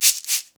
African Shekere 4.wav